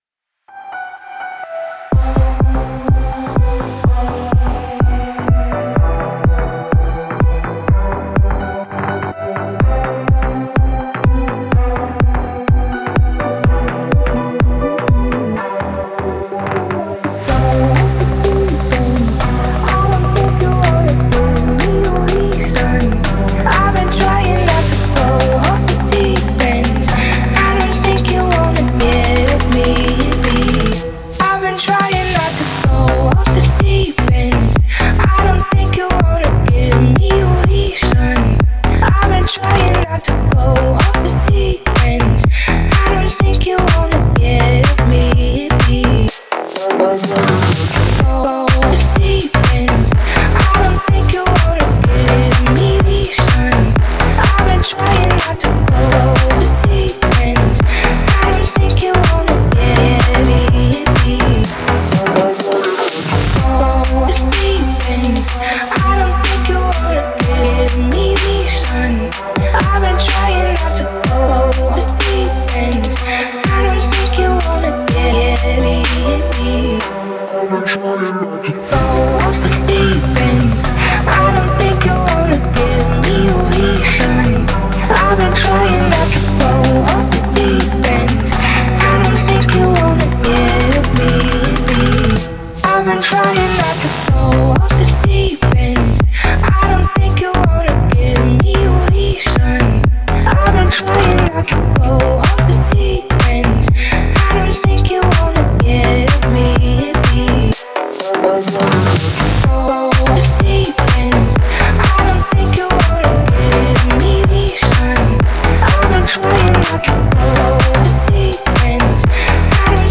которая сочетает в себе элементы R&B и электронной музыки.
плавно переплетается с атмосферными синтезаторами.